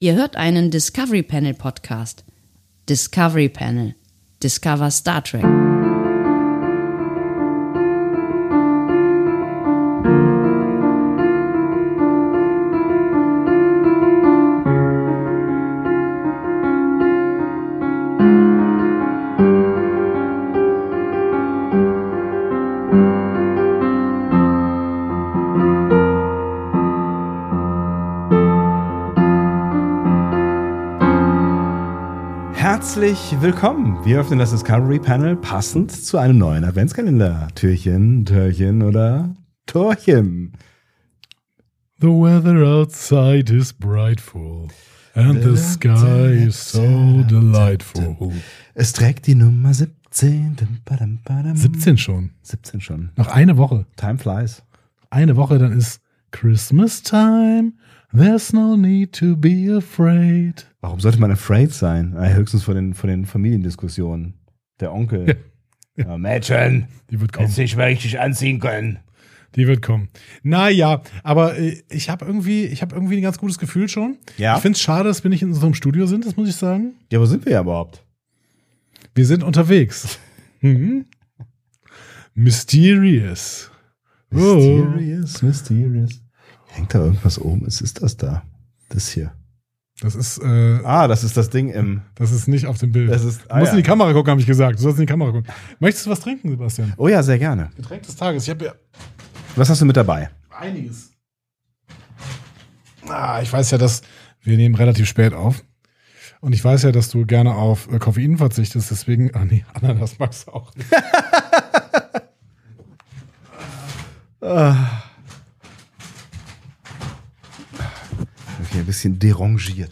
Wir sind NICHT im Studio. Wir sind unterwegs. Im Hotelzimmer.